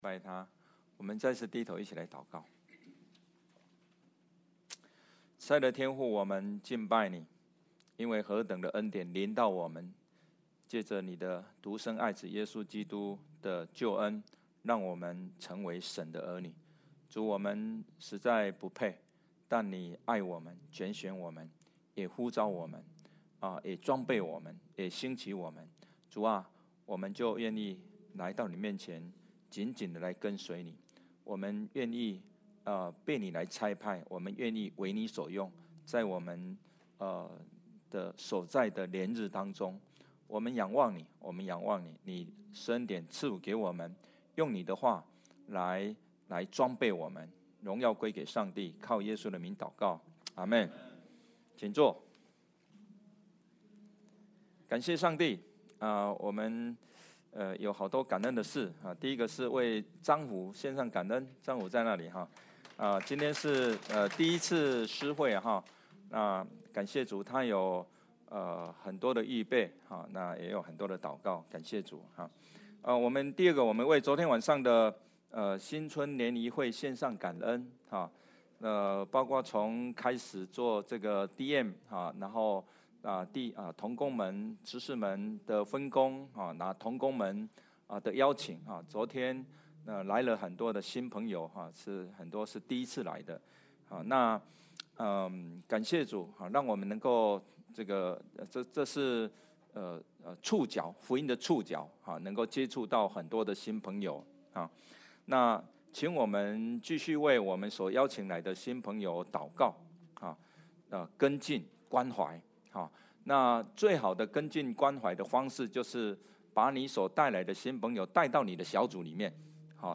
Passage: Matthew 9:35–10:10 Service Type: 主日证道 Download Files Notes « 心靈重建 天国的奥秘 » Submit a Comment Cancel reply Your email address will not be published.